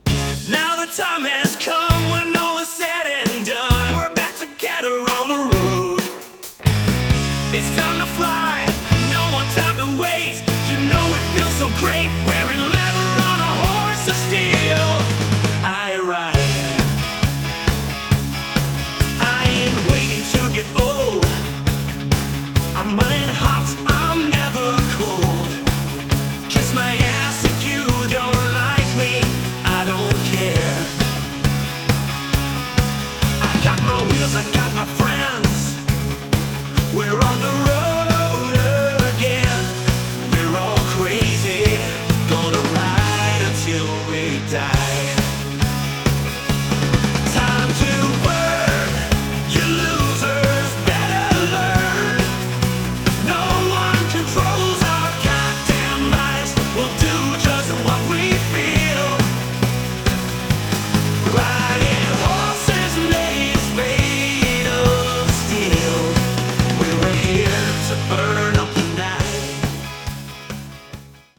Heavy Metall
Та же самая тональность.